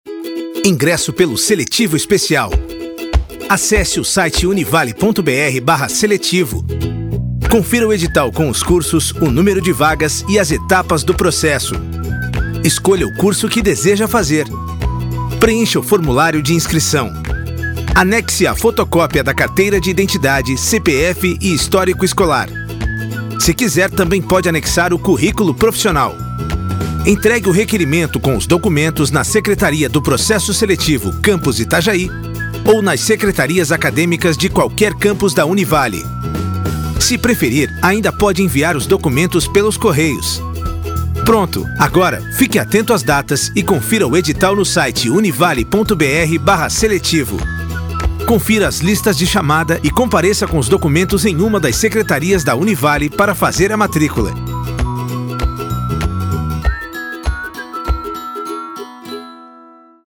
• documentário